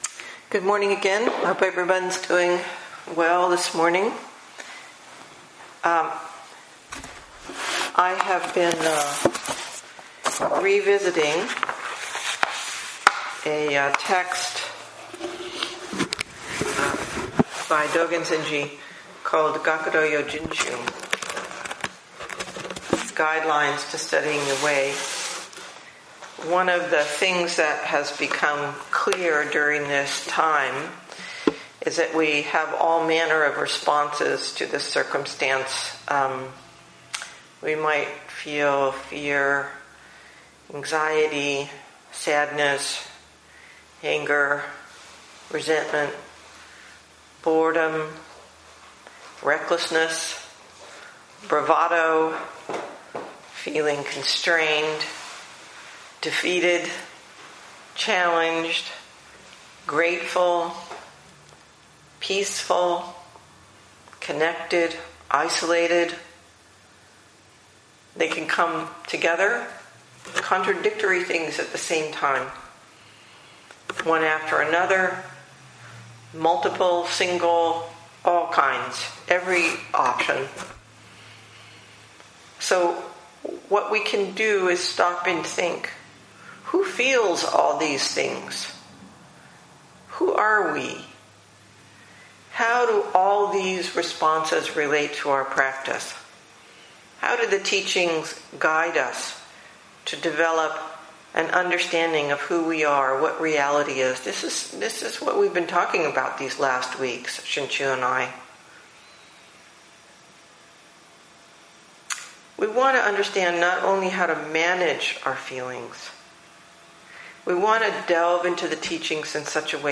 2020 in Dharma Talks